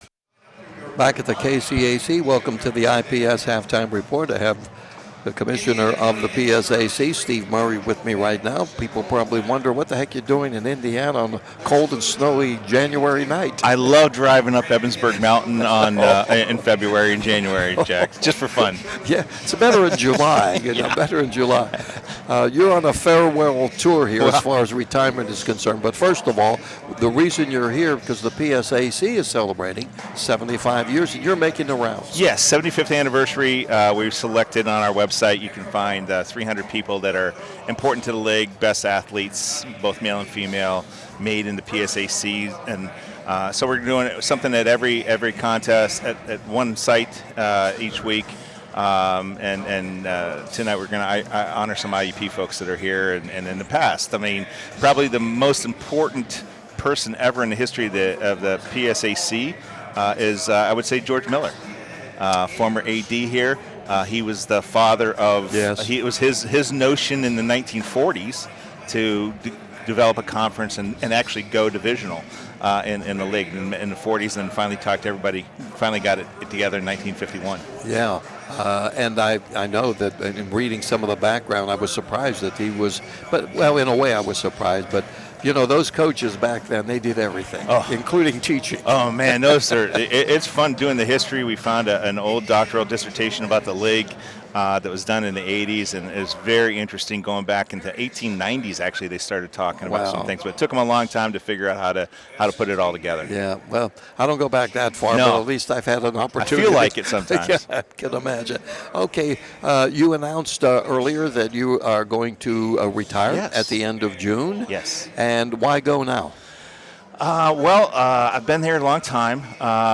Here’s the interview: